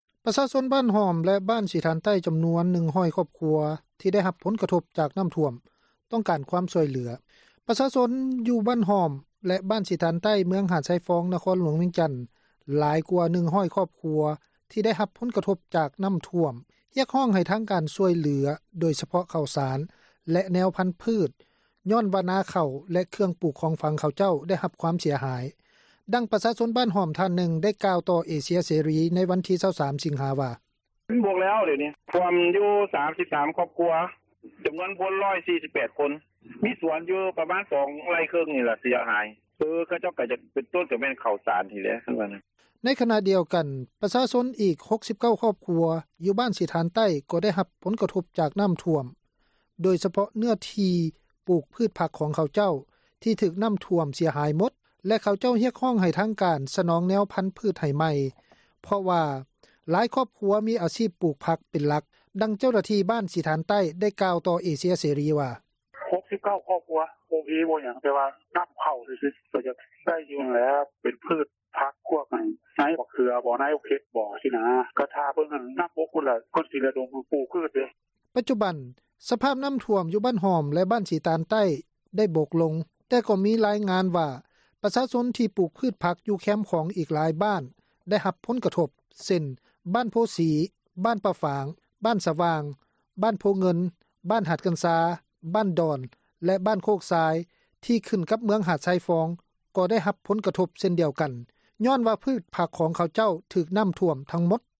ດັ່ງປະຊາຊົນບ້ານຫ້ອມ ທ່ານນຶ່ງກ່າວຕໍ່ເອເຊັຍເສຣີ ໃນວັນທີ 23 ສິງຫາ ວ່າ: